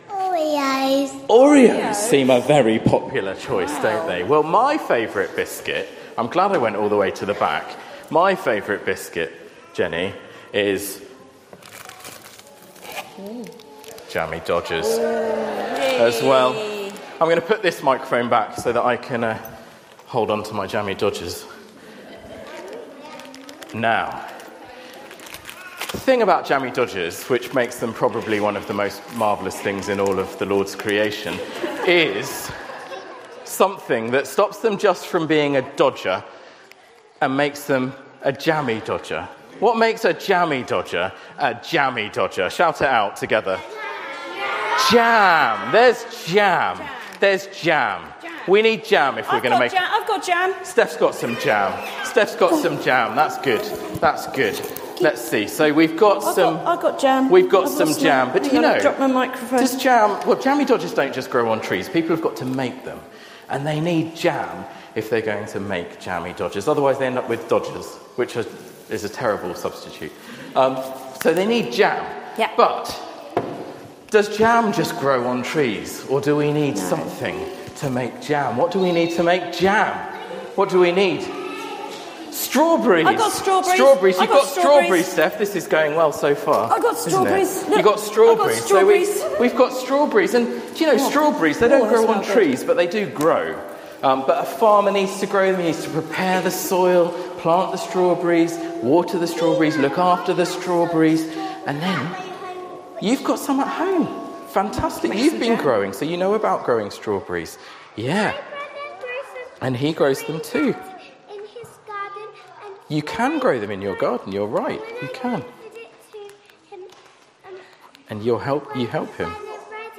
Recordings of sermons preached at St Andrew's, along with talks given at other times, are available from this site.